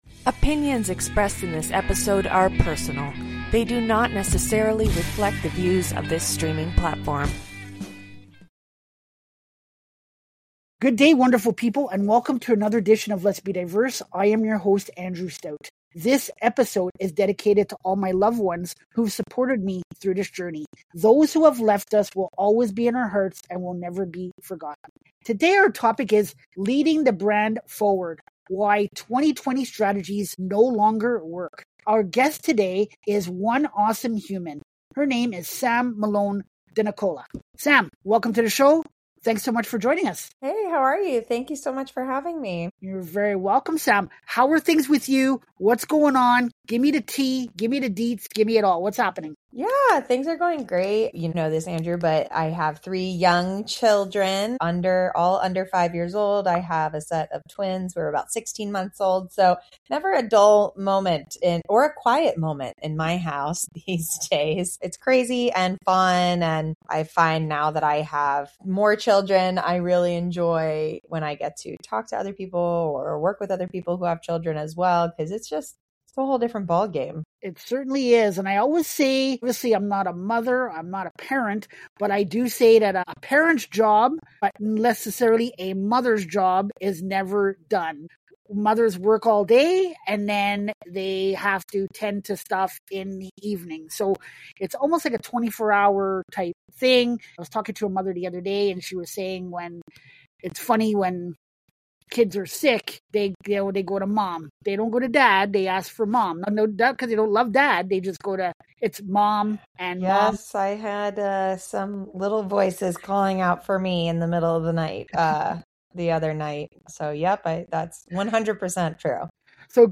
This podcast aims to discuss common issues and solutions in Human Resources in globally for businesses of all sizes. We focus on having a light, approachable conversation about current business issues and needs and how we can solve them together by offering our opinions and expertise and sharing personal stories.